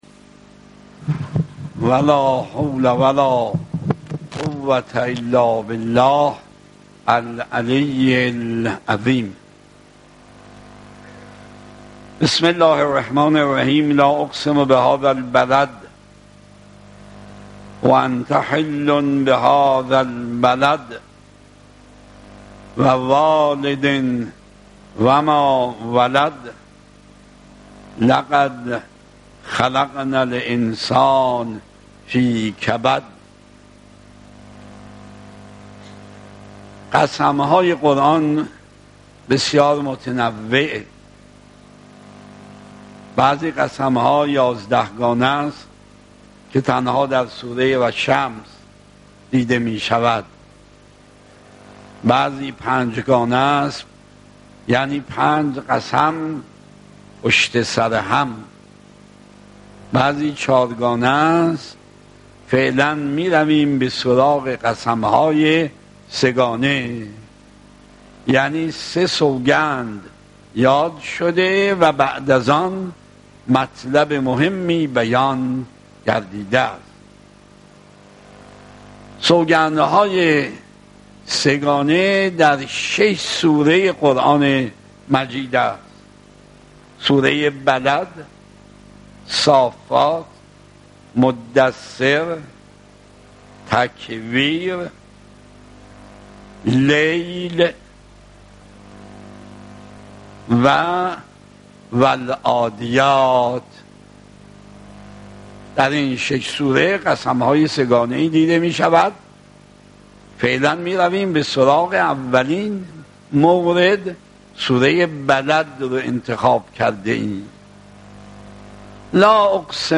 سخنرانی آیت‌الله مکارم شیرازی با موضوع سوگندهای قرآنی
سخنرانی آیت‌الله العظمی مکارم شیرازی با موضوع سوگندهای قرآنی 27 بخشی